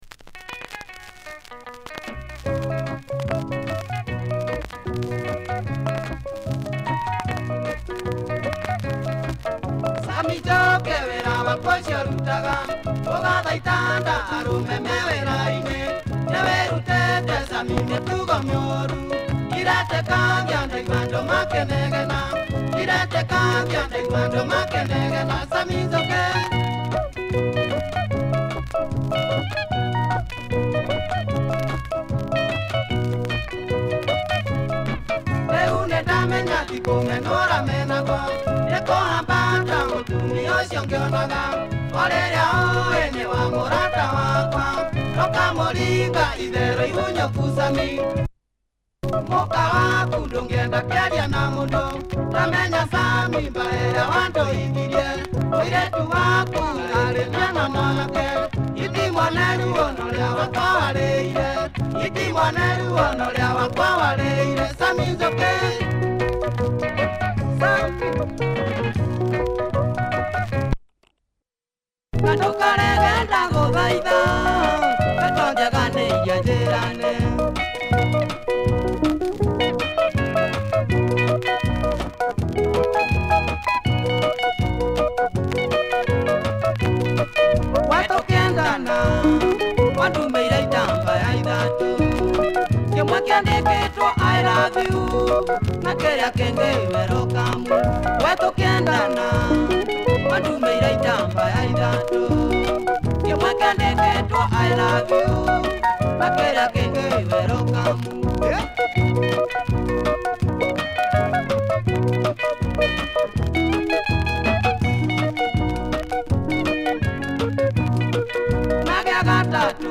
Benga from this prolific Kikuyu group.